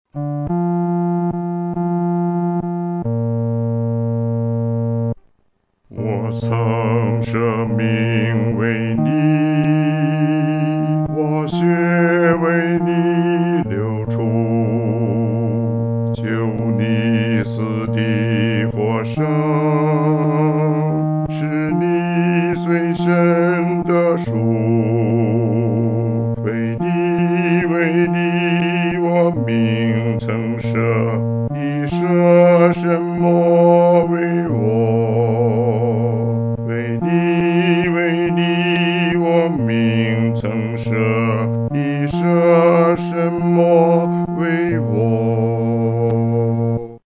独唱（第四声）
我曾舍命为你-独唱（第四声）.mp3